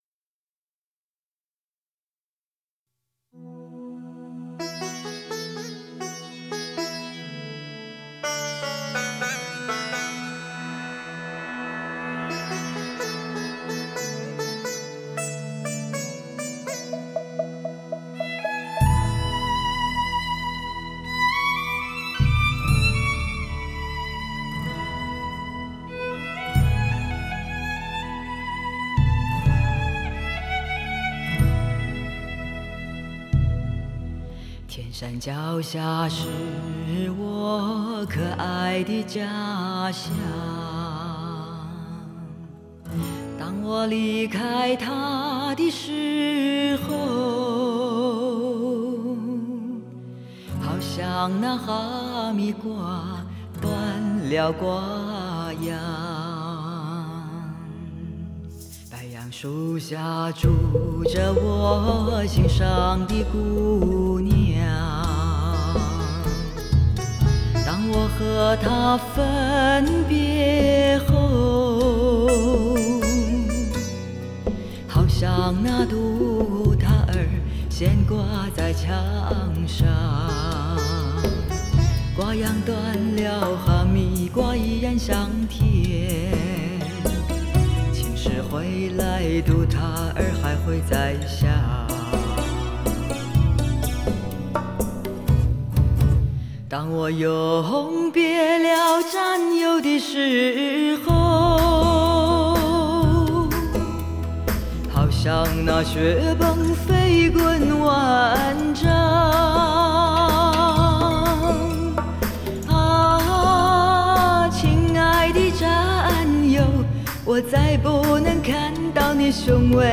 全球首张发烧级民乐DTS多声道CD，发烧经典，
带来超乎想象震憾性环绕声体验。
中国大陆的顶级发烧女声天碟，
无可比拟的北性女声，体验极度发烧唱片的真谛！